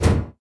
ladder5.wav